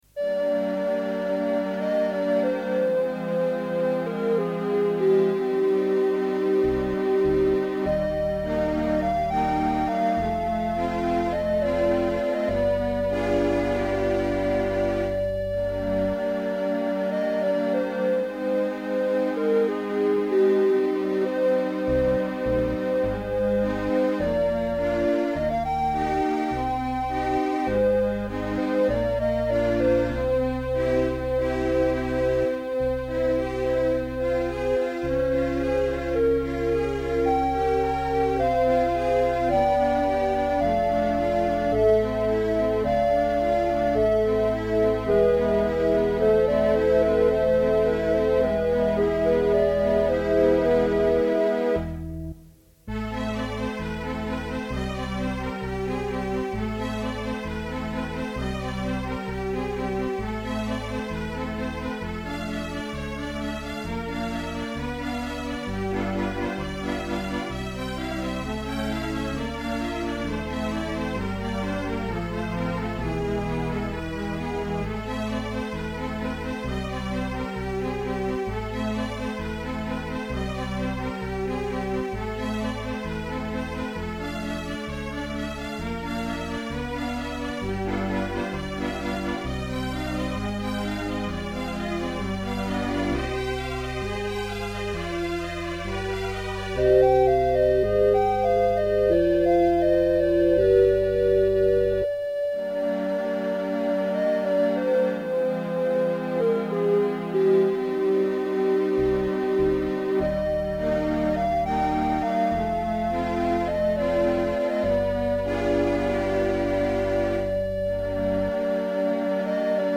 (modern / classical style)